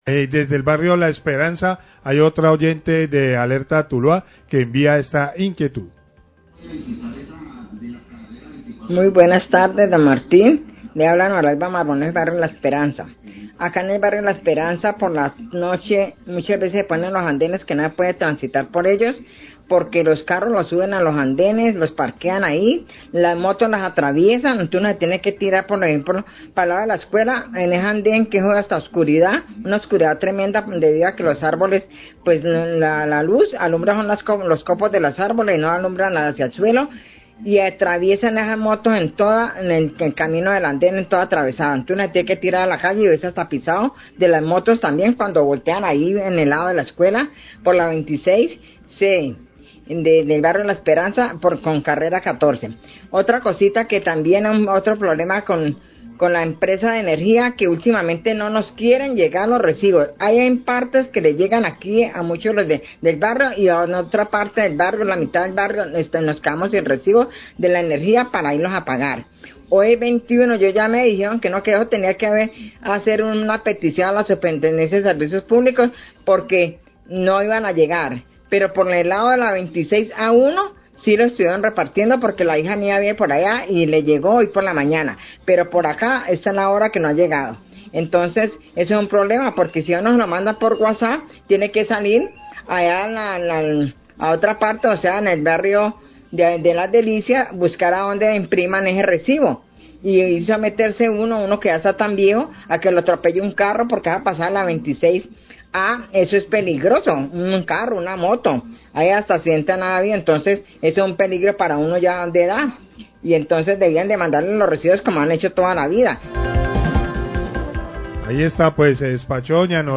Radio
El Secretario de Gobierno de Tuluá, José Martín HIncapie, recibe inquietudes de lso tulueños y una señora del barrio La Esperanza denuncia que a algunas viviendas de ese barrio no están llegando las facturas de energía.